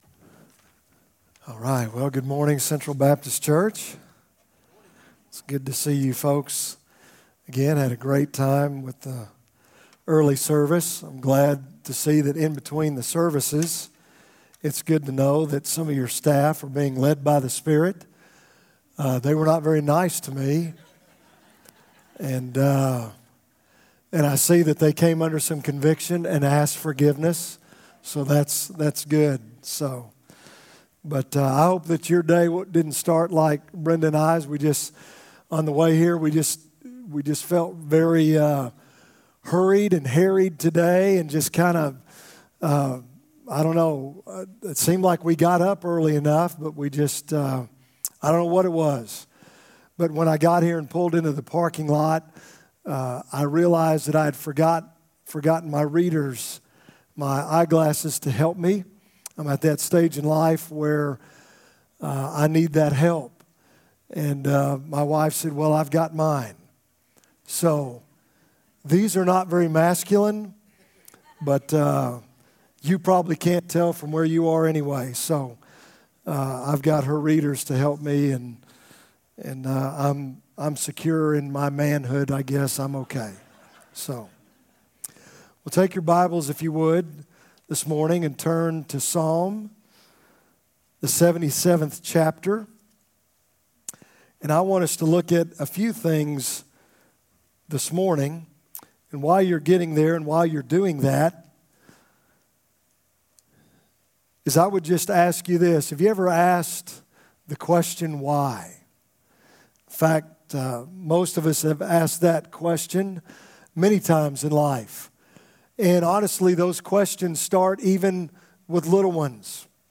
From Series: "2018 Sermons"
Our 10:45 am service on Sept. 23, 2018